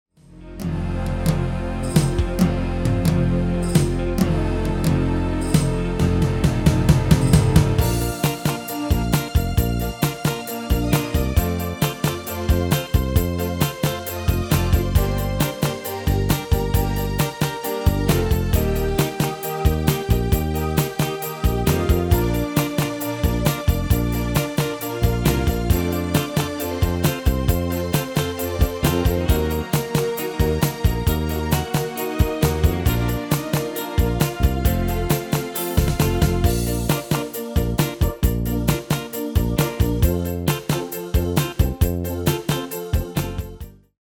Demo's zijn eigen opnames van onze digitale arrangementen.